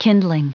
Prononciation du mot kindling en anglais (fichier audio)
Prononciation du mot : kindling